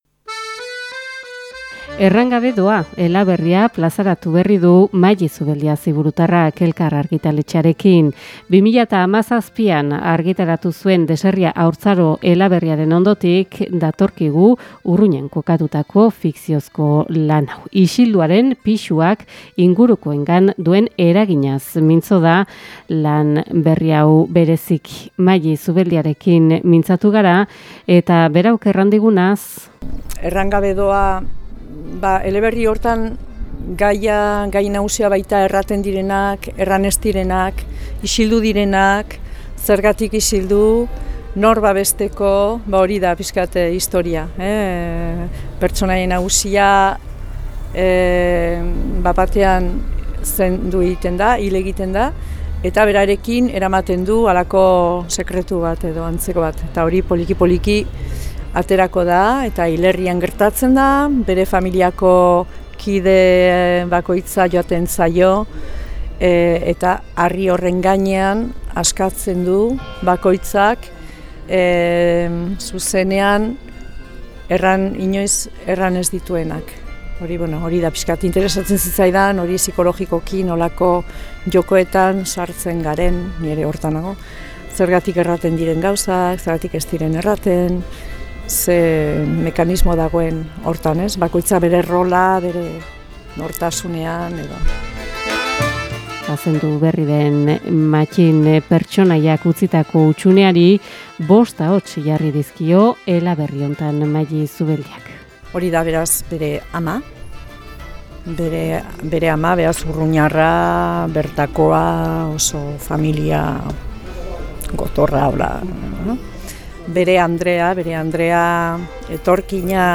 Antxeta irratiko lagunek egindako erreportajea ekarri dugu gurera.